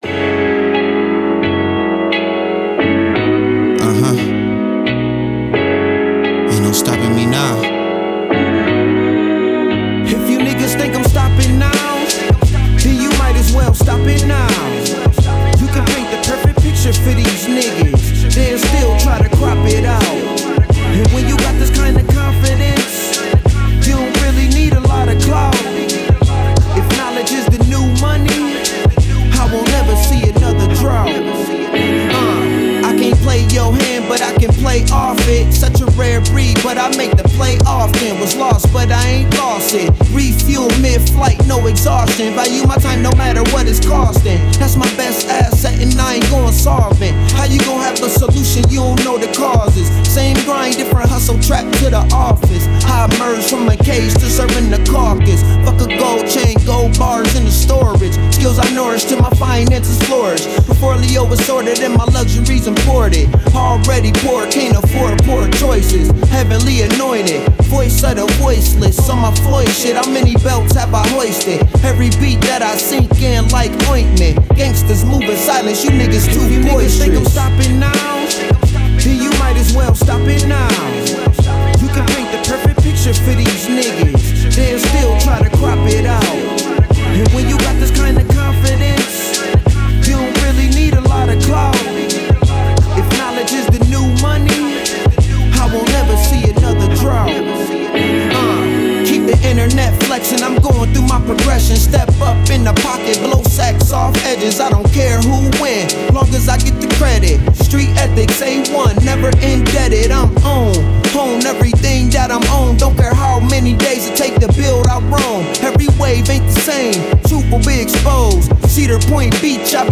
Hiphop
Description : Street Intellectual and motivating Hip Hop.